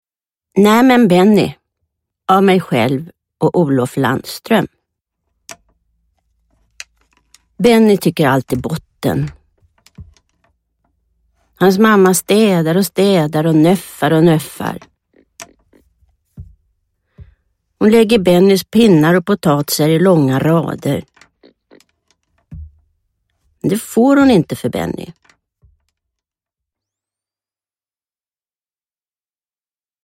Nämen Benny – Ljudbok – Laddas ner
Uppläsare: Barbro Lindgren